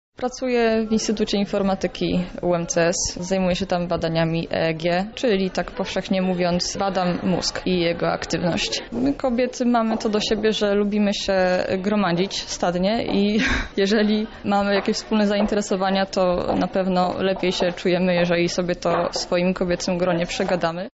O swoich swoim temacie i o Geek Girls Carrots mówi także prelegentka